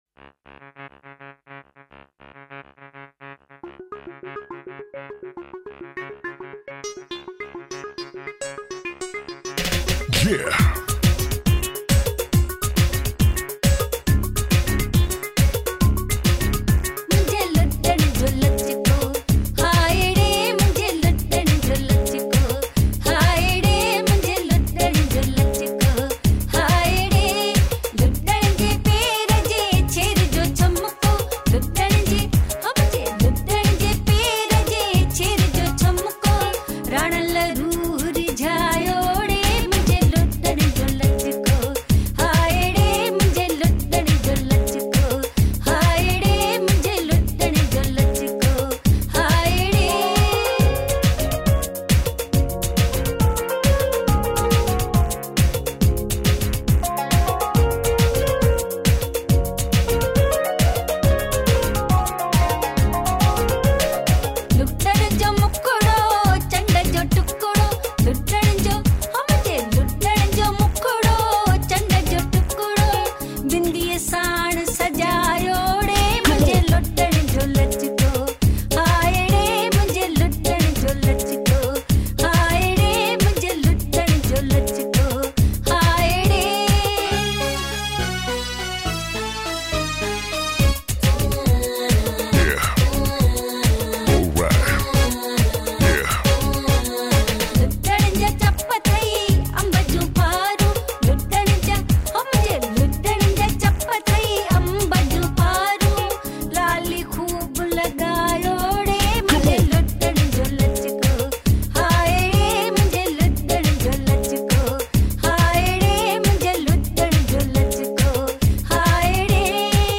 Sindhi POP